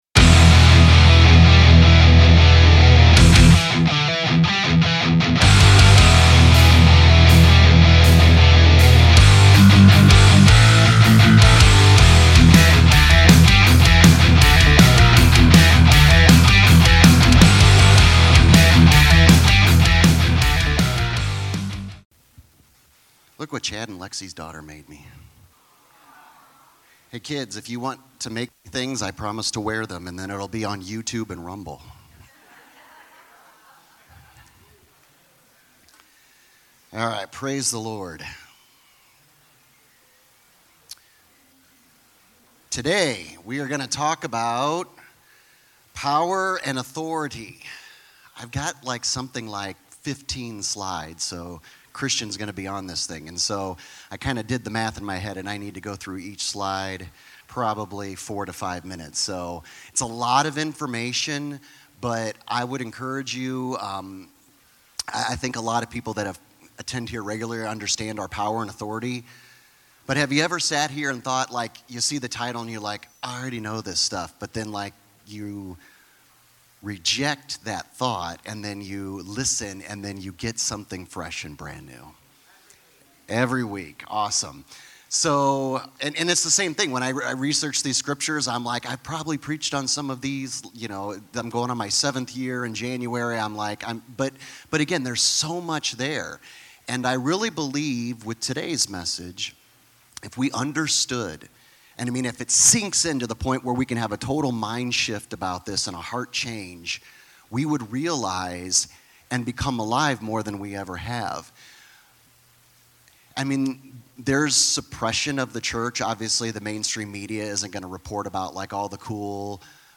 Sermons | 7 Mountains Church